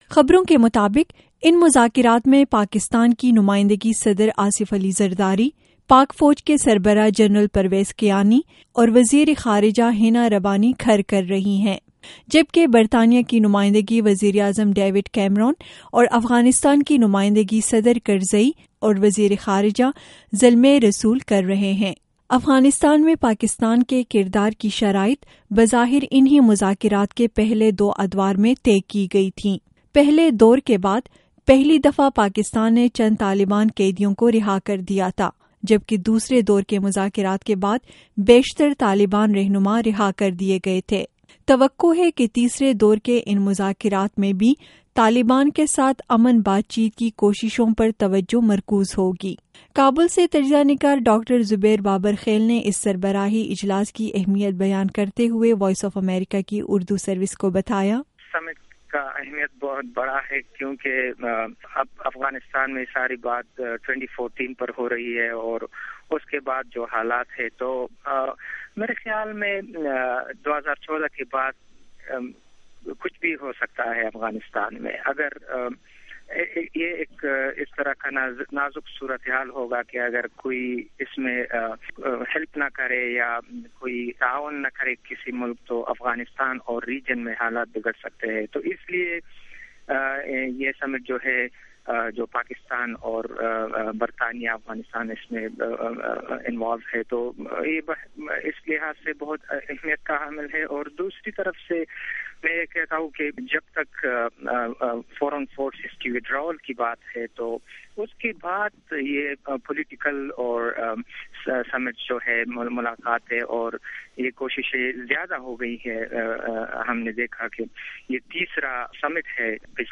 سہ فریقی افغان کانفرنس, تجزیہ